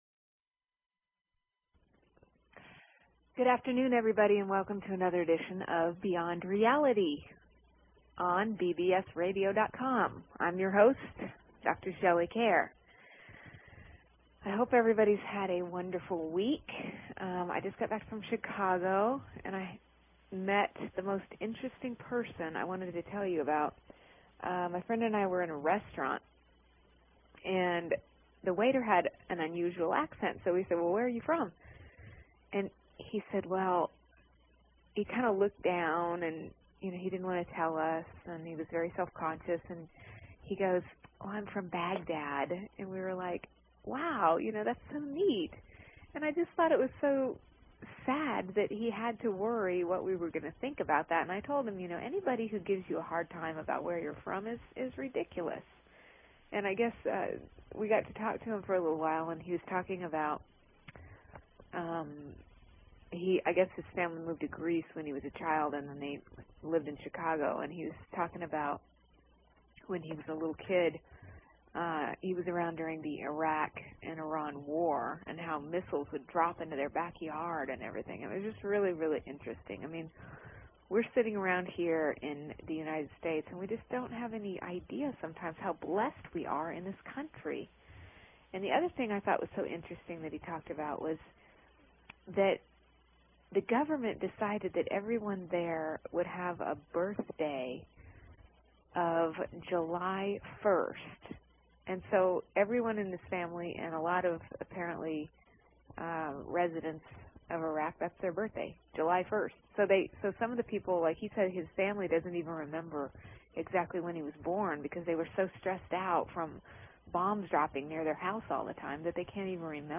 Talk Show Episode, Audio Podcast, Beyond_Reality and Courtesy of BBS Radio on , show guests , about , categorized as